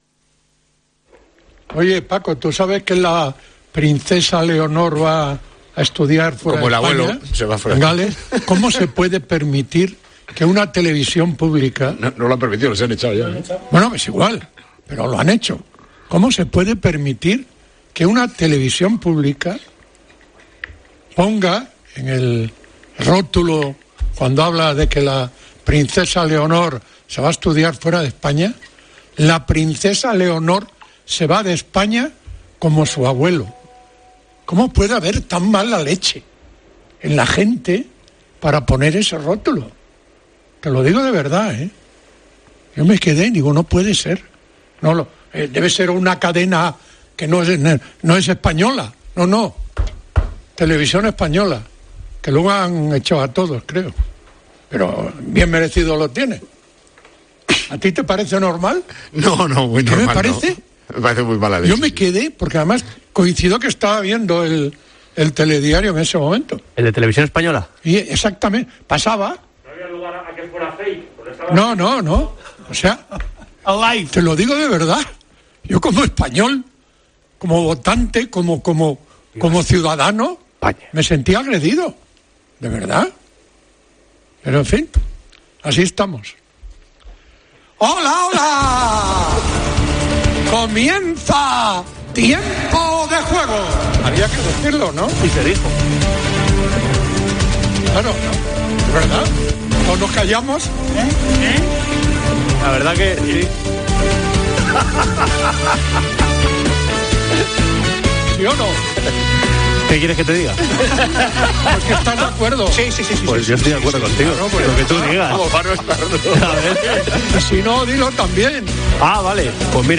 Genio y figura, con su peculiar estilo, así arrancó Tiempo de Juego tras ver el desafortunado rótulo sobre la princesa Leonor